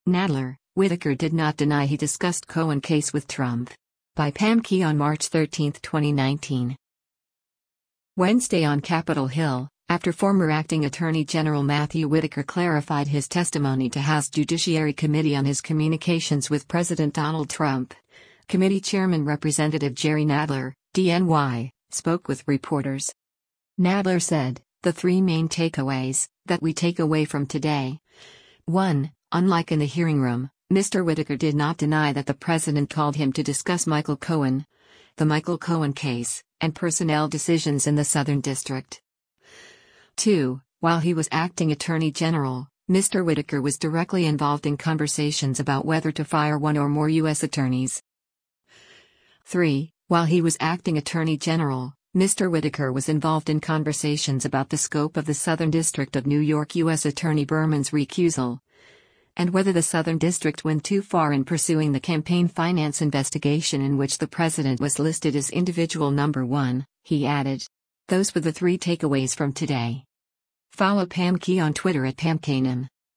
Wednesday on Capitol Hill, after former acting Attorney General Matthew Whitaker clarified his testimony to House Judiciary Committee on his communications with President Donald Trump, committee chairman Rep. Jerry Nadler (D-NY) spoke with reporters.